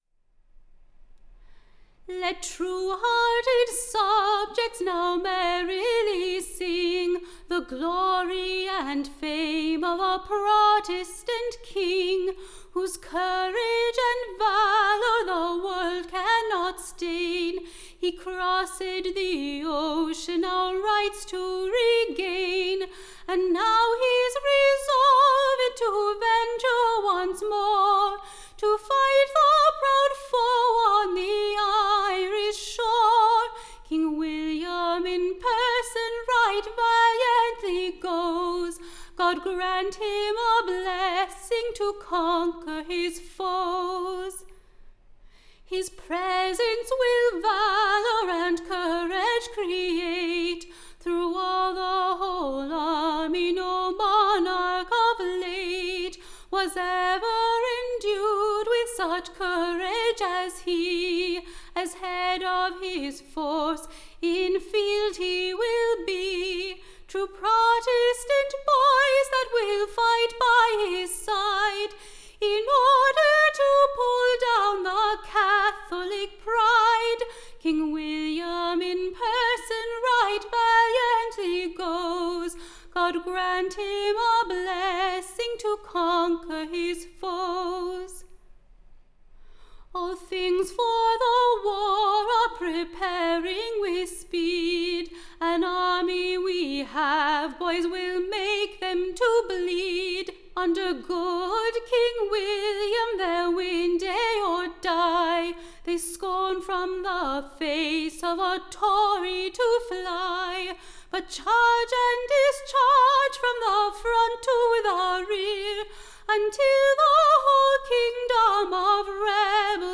EBBA 20934 - UCSB English Broadside Ballad Archive